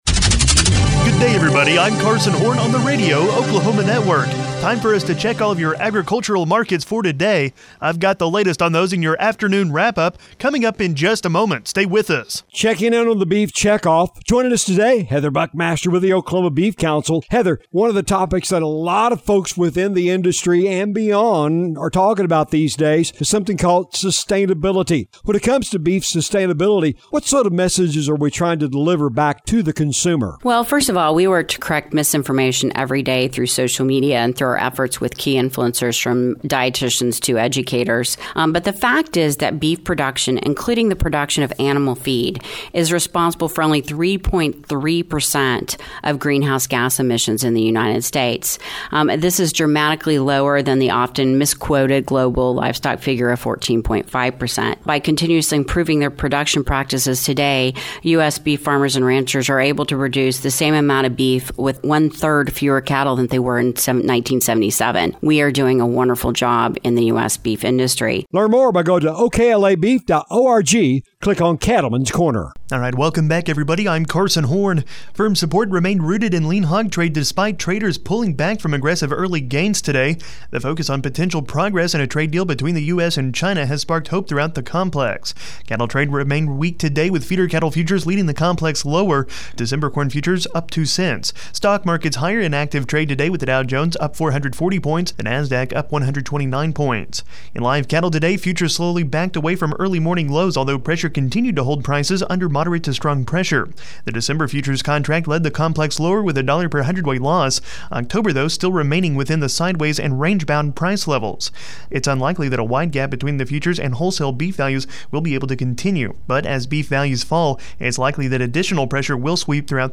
Thursday Afternoon Market Wrap-Up